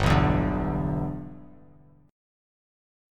G6 Chord
Listen to G6 strummed